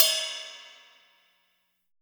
MTLRIDE BEL.wav